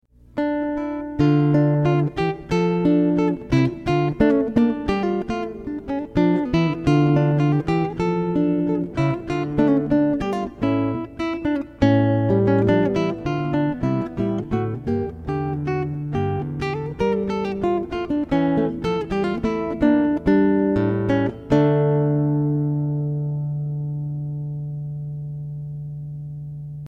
Classical Guitar: